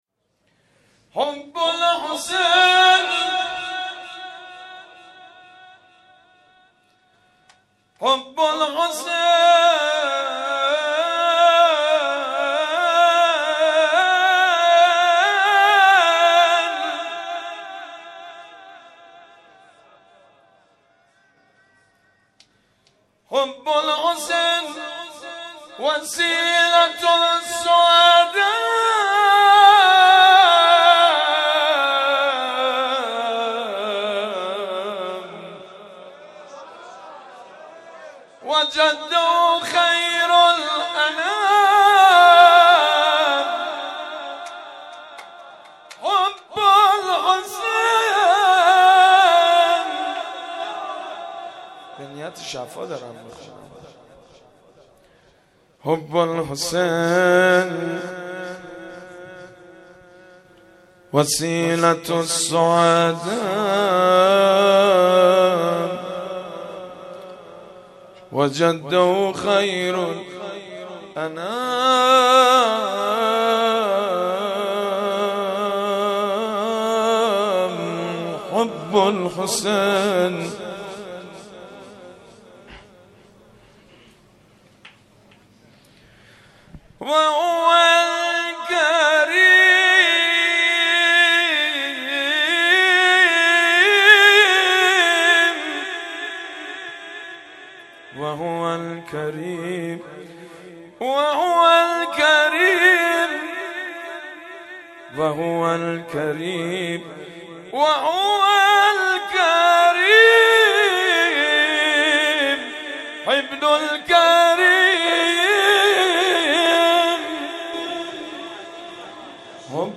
دو دمه